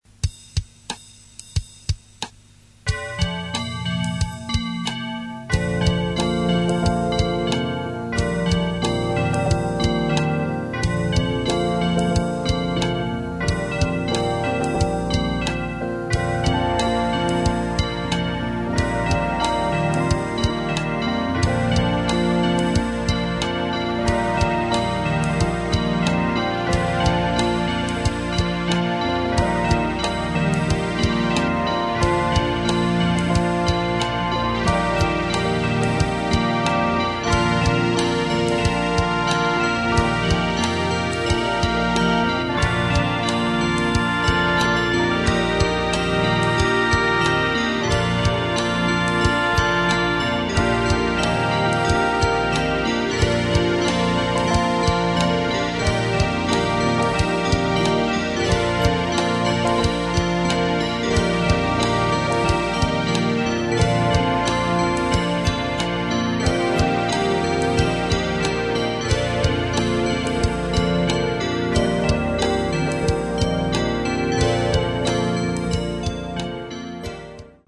Während des Songs erreicht er die Hügelkuppe und sieht unten TRIVIA stehen, gekleidet wie am Anfang, das BEGINNING Thema wird zum LOVER Thema ( ist ja das Gleiche, nur in Dur) und steigert sich ins FINALE, kurz bevor die beiden einander erreichen –
13tlstinterludeweddingbells.mp3